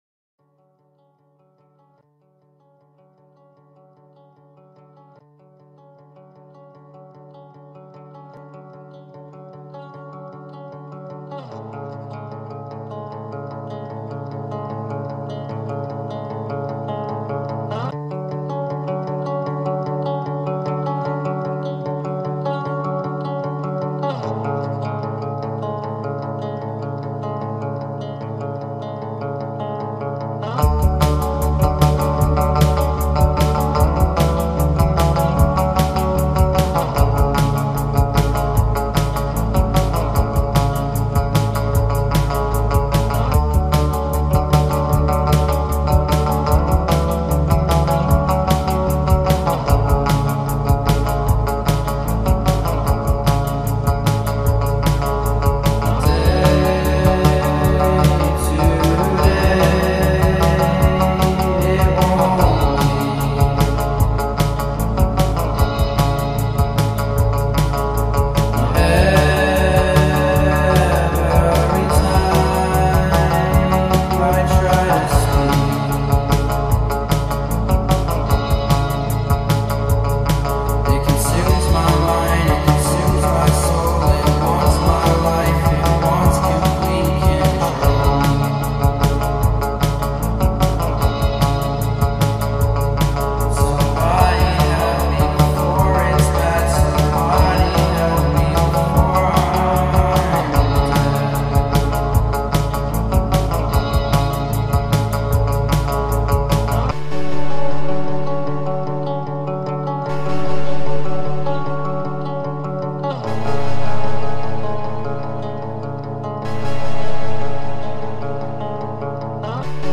غمگین